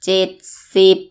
_ jedd _ sibb